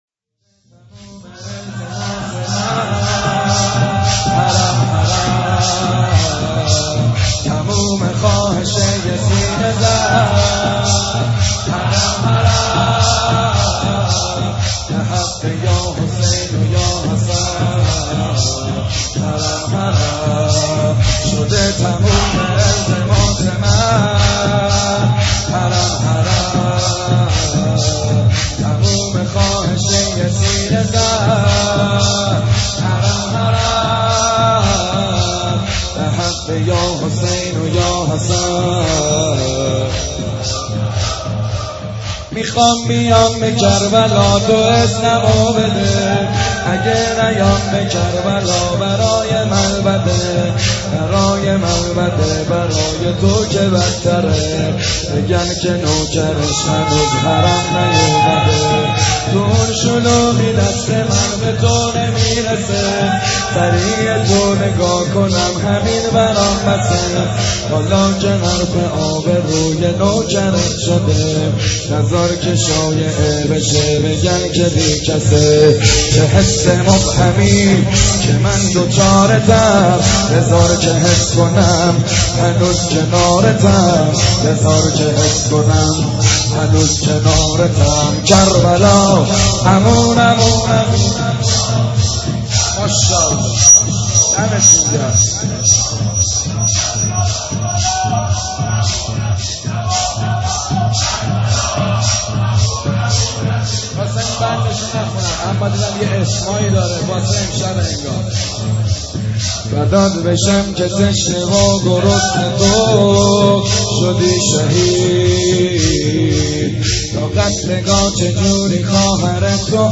شور - تموم التماس من حرم حرم